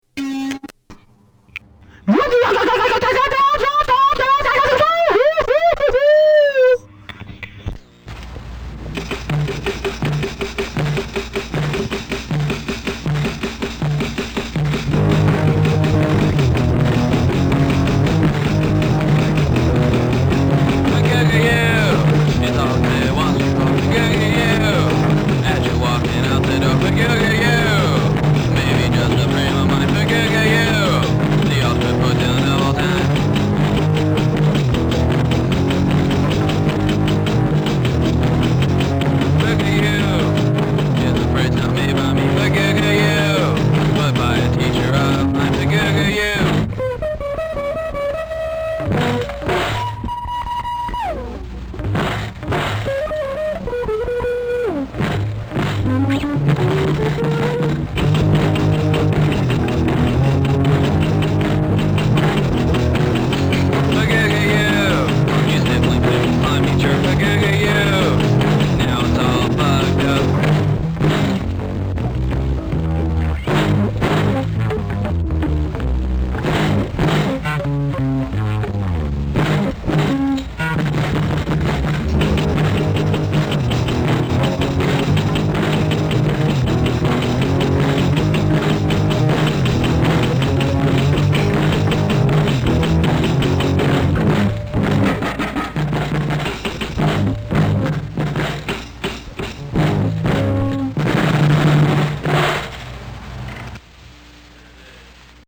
Vocal
Guitars, Bass, Caveman Drums, Introductory Howl